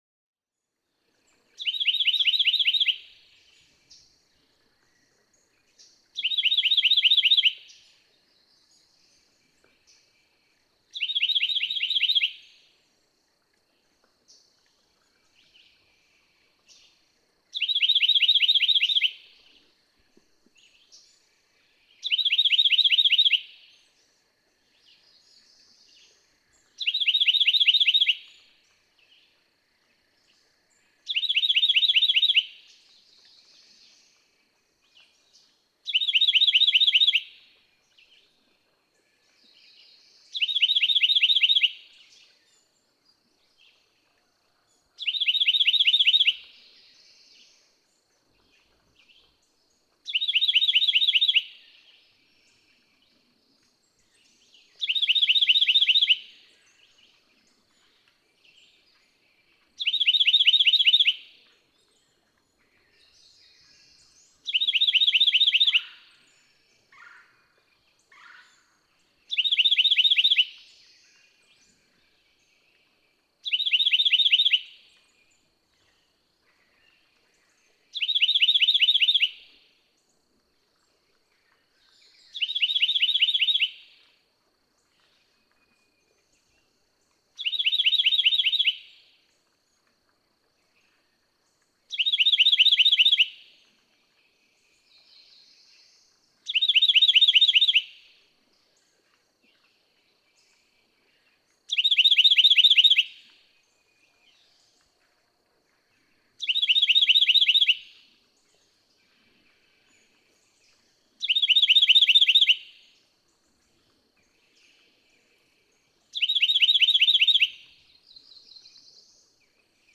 Carolina wren
Adult song. How many songs of just one type?
Redbird Wildlife Management Area, Daniel Boone National Forest, Big Creek, Kentucky.
184_Carolina_Wren.mp3